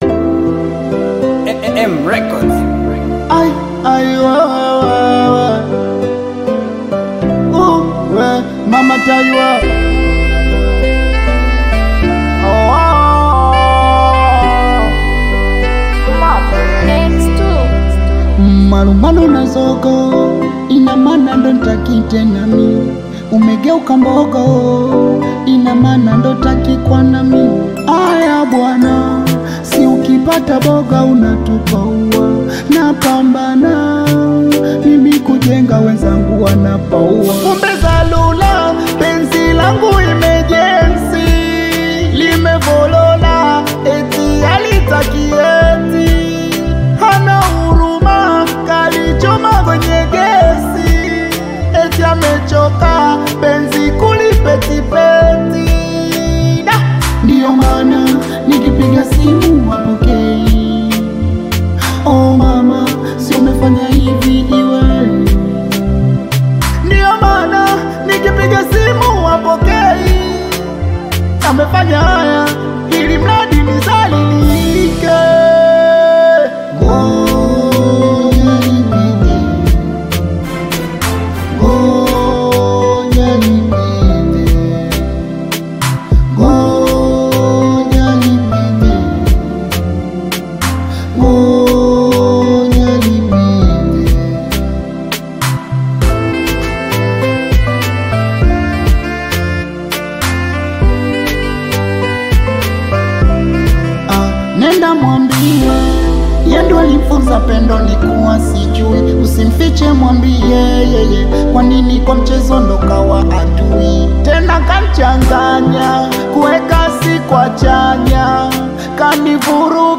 AudioBongo flava